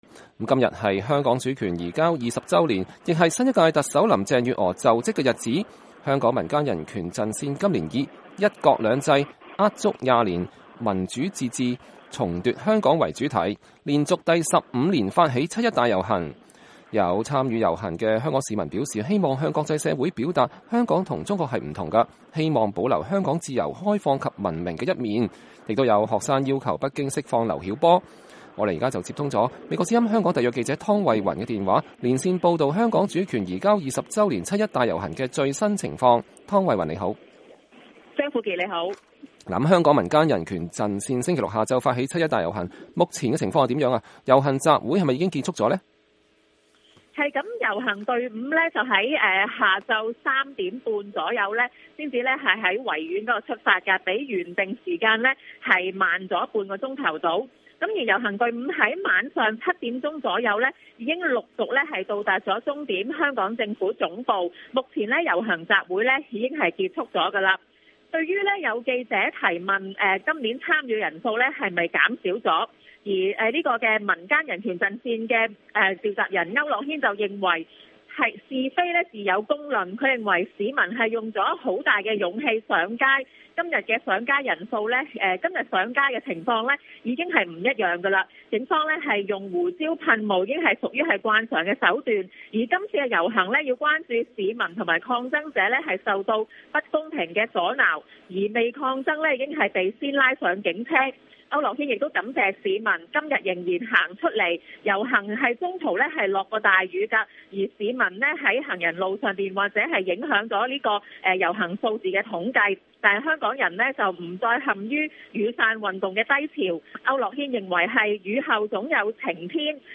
香港主權移交20週年 7-1大遊行現場報導